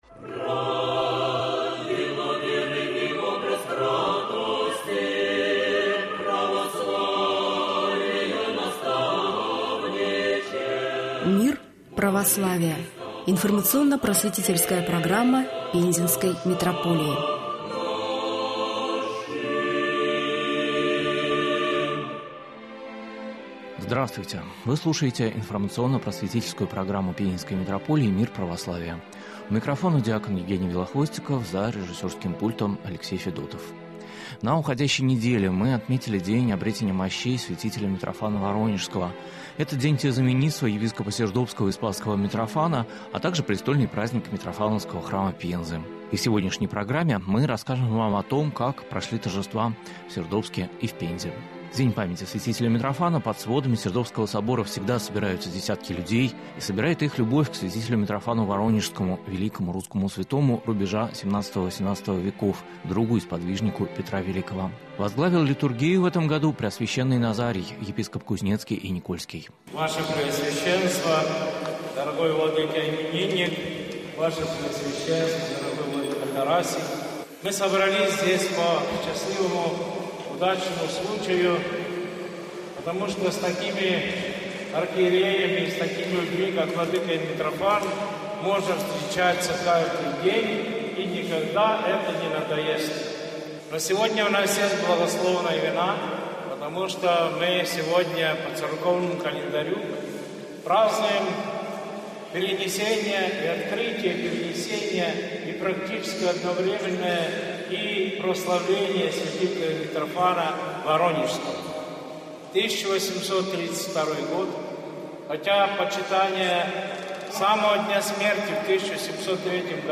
В новом выпуске информационно-просветительской программы Пензенской митрополии «Вопросы веры» вниманию телезрителей предлагается репортаж с места событий.